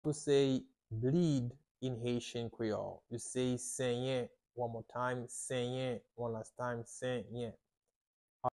How to say "Bleed" in Haitian Creole - "Senyen" pronunciation by a native Haitian Creole teacher
“Senyen” Pronunciation in Haitian Creole by a native Haitian can be heard in the audio here or in the video below:
How-to-say-Bleed-in-Haitian-Creole-Senyen-pronunciation-by-a-native-Haitian-Creole-teacher.mp3